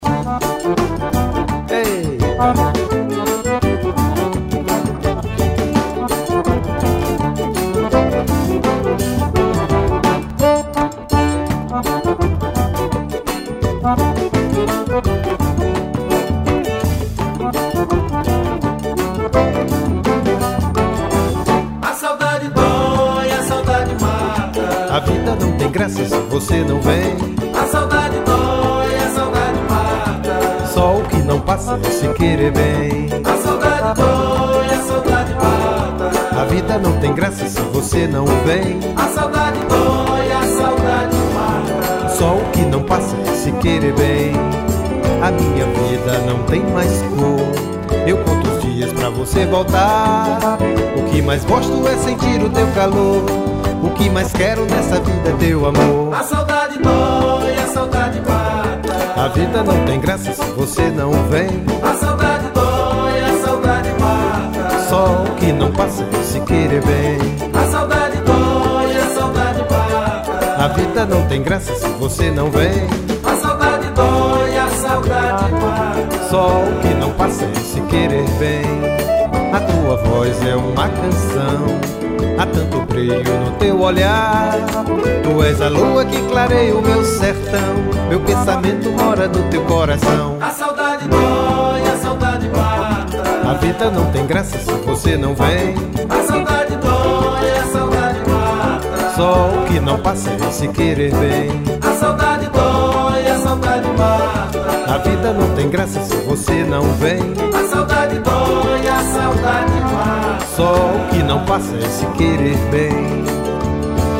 1085   01:53:00   Faixa:     Forró